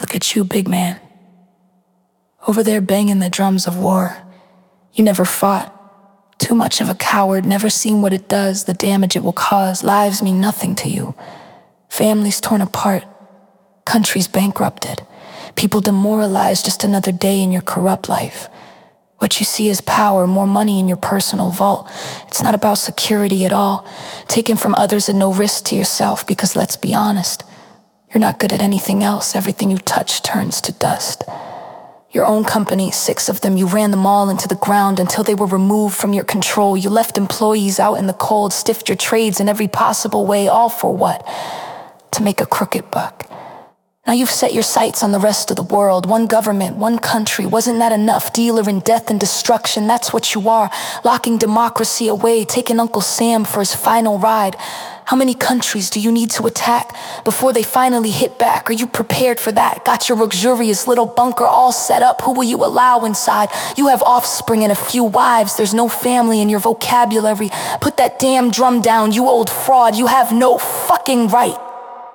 Silence-the-Drums-spoken.mp3